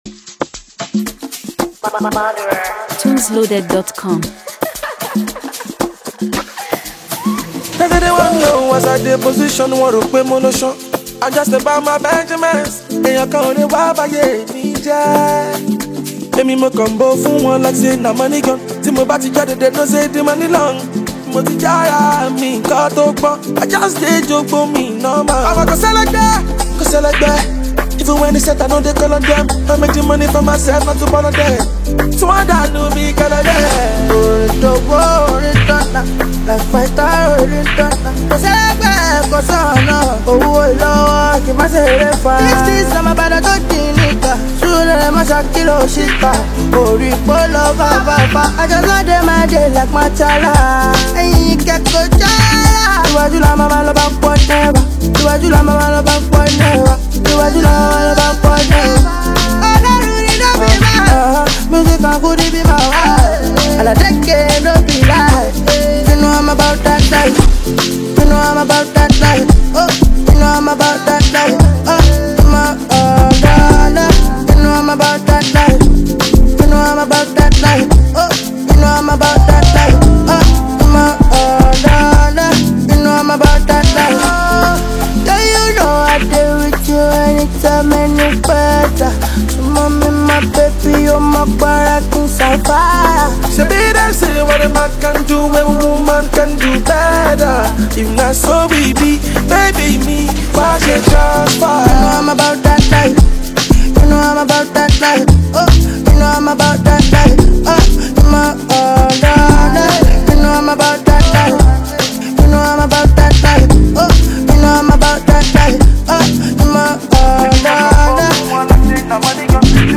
rapper
powerful vocals and signature Yoruba-infused delivery
is a mid-tempo Afro-fusion tune